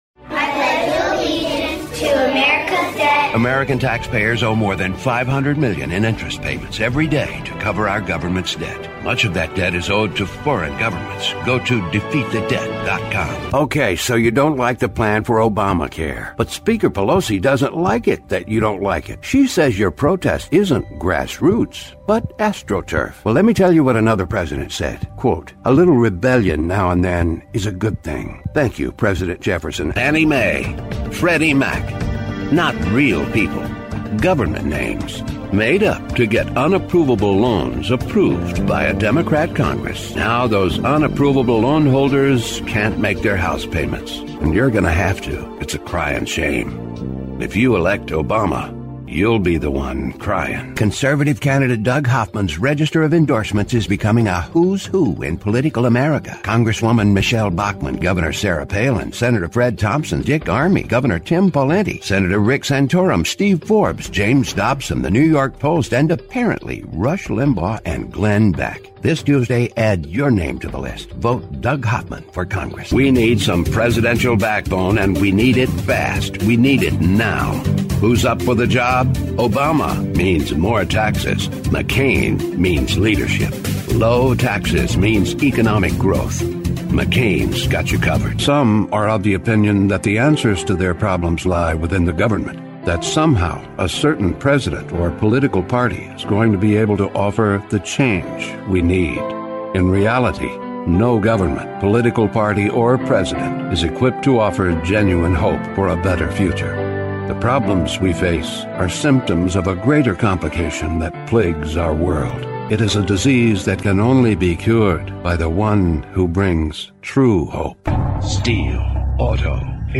Middle Aged
Quiet in-house audio booth. Classic 1981 Neumann U-87.